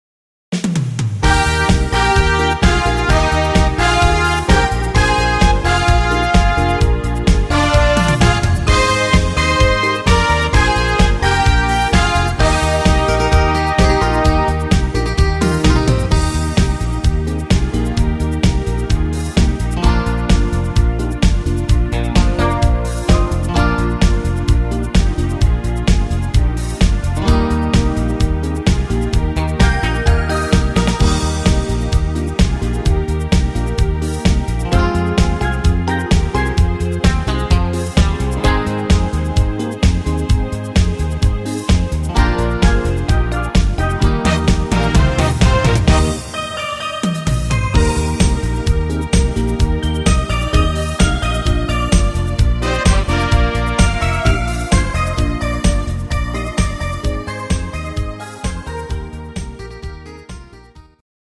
MP3 Playbacks1
Rhythmus  Discofox
Art  Schlager 90er, Deutsch